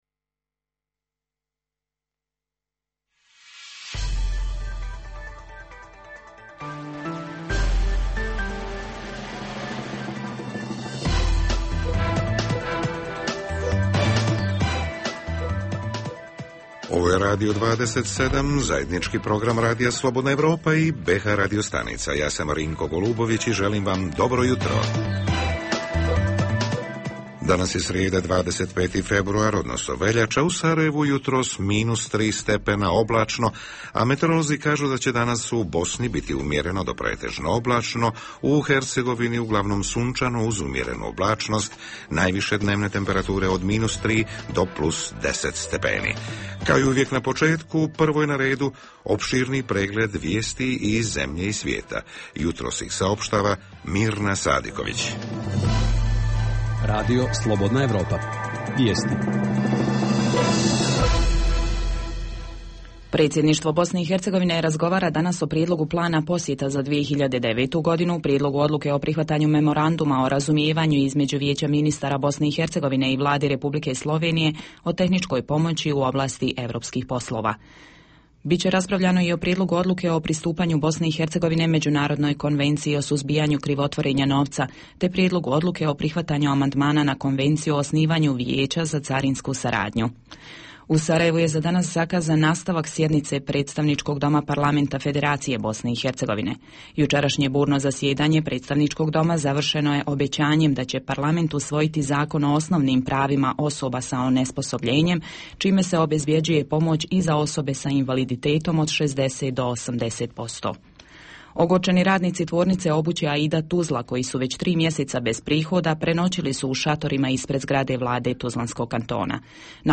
U jutarnjem programu za BiH koji se emituje uživo pitamo: da li je voda koju pijemo bakteriološki i hemijski ispravna?